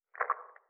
splat.wav